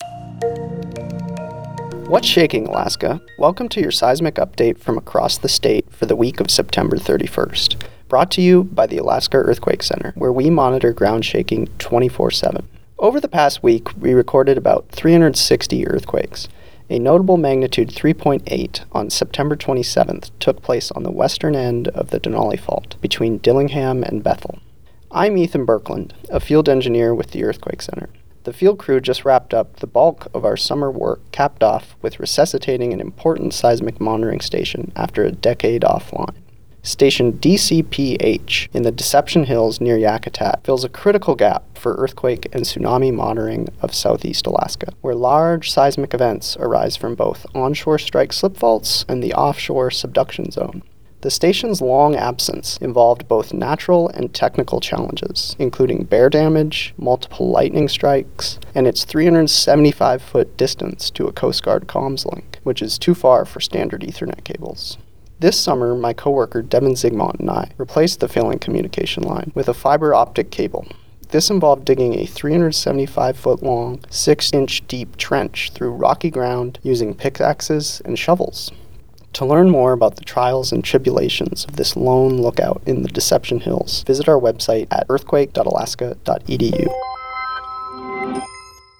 Our radio broadcast describing the trials and tribulations of a critical station's recovery process: